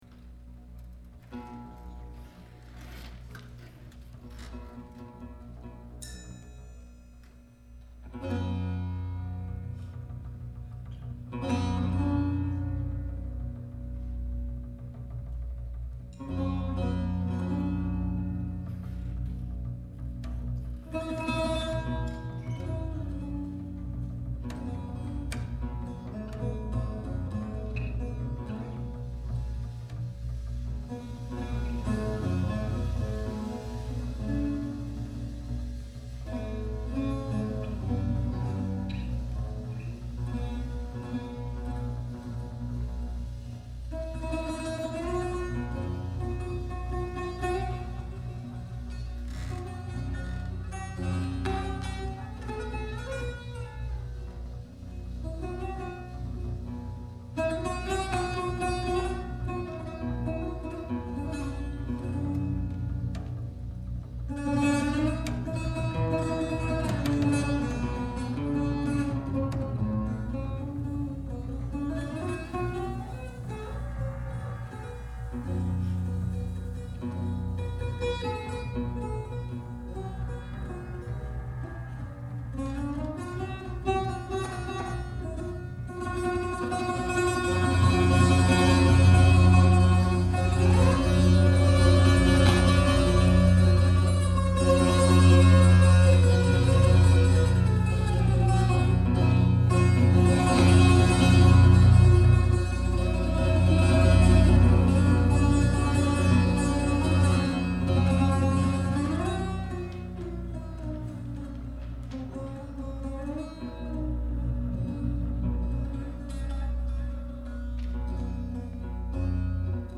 Buzuq, Mijwiz, Nay, Rababa, Salamiyya, Ud
Acoustic Bass
Tabla, Percussion, Electronics, Metals
Trio